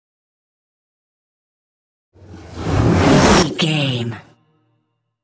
Trailer dramatic raiser short
Sound Effects
Atonal
futuristic
intense
tension
dramatic
riser